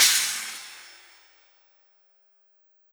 Cymbol Shard 04.wav